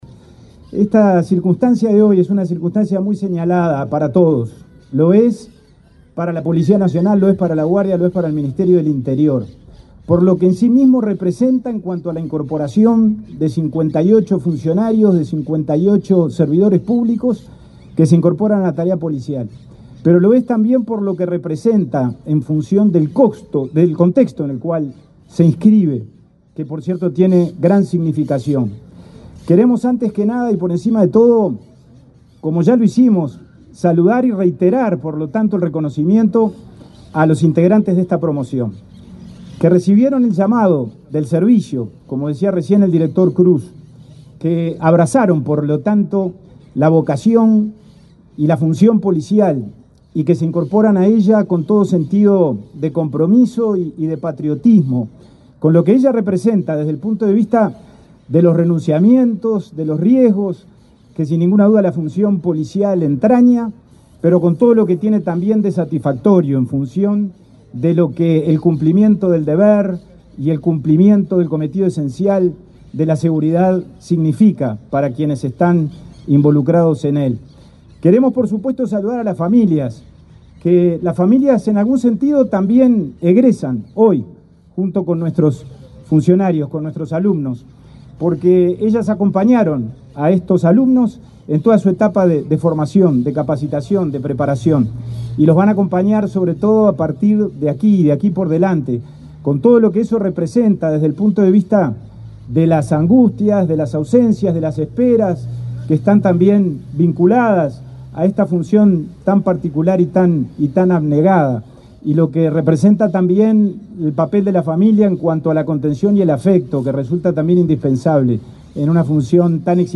Palabras del subsecretario del Interior, Pablo Abdala
Palabras del subsecretario del Interior, Pablo Abdala 16/12/2024 Compartir Facebook X Copiar enlace WhatsApp LinkedIn Este lunes 16 en Montevideo, el subsecretario del Ministerio del Interior, Pablo Abdala, participó en la ceremonia de egreso de oficiales de la Guardia Republicana.